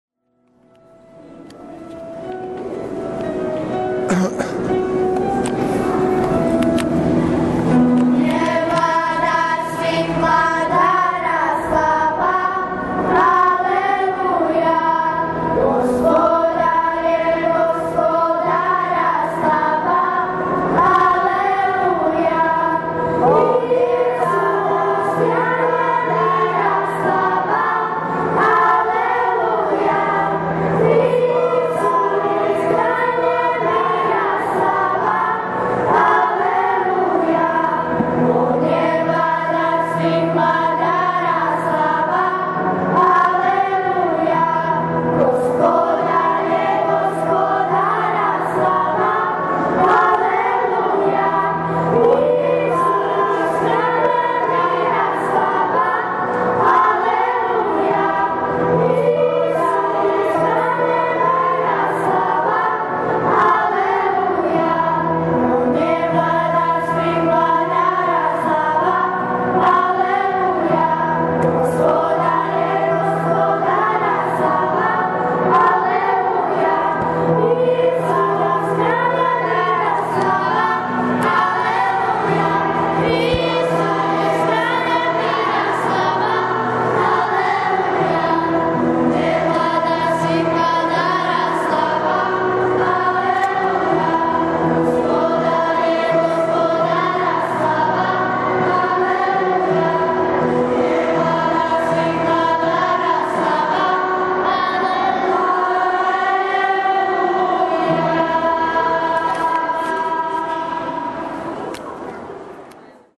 ZBOR DJECE i MLADIH – AUDIO:
završna pjesma – ZBOR DJECE i MLADIH